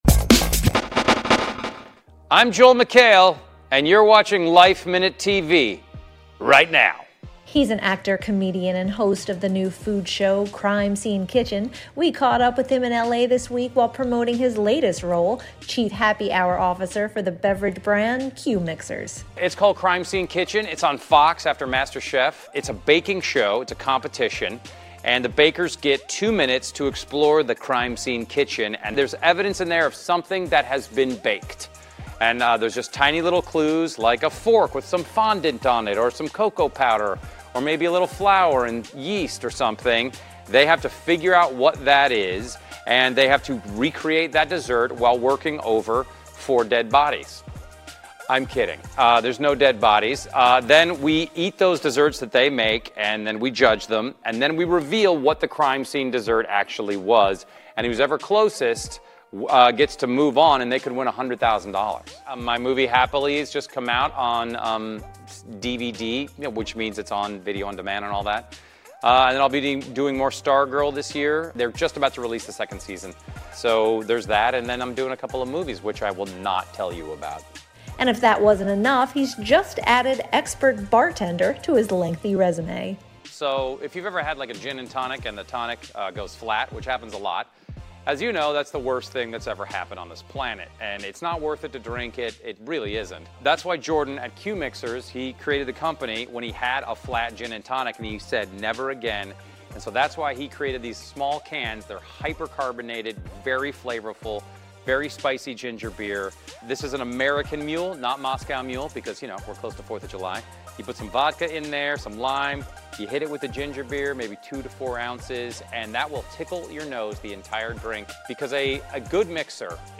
He’s an actor, comedian, and host of the new food show Crime Scene Kitchen. We caught up with him in LA this week while promoting his latest role, Chief Happy Hour Officer for the beverage brand Q Mixers.